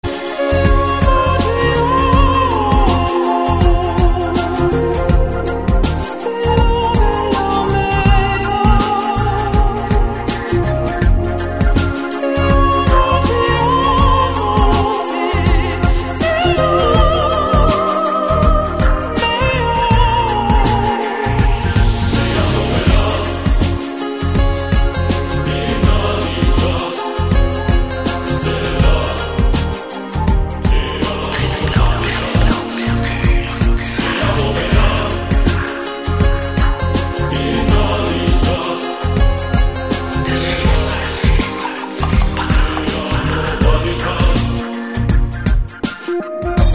• Качество: 128, Stereo
мужской голос
женский вокал